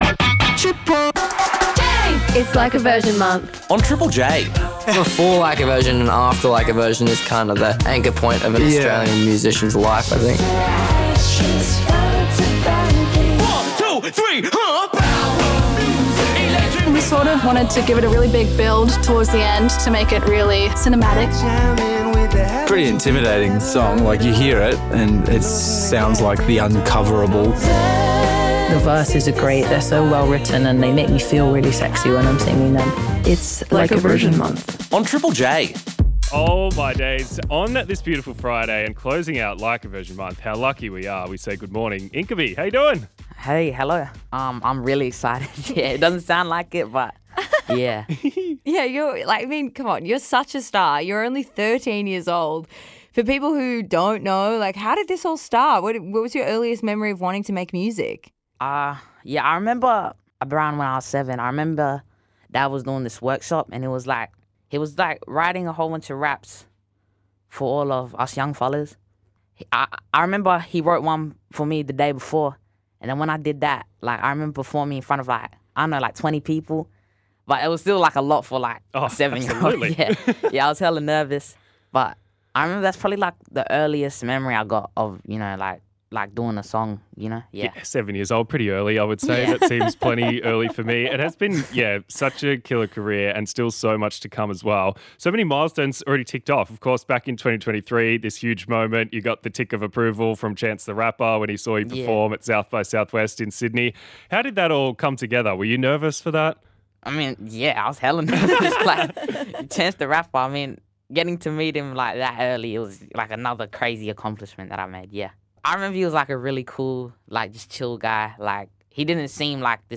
floor filler, choir and all.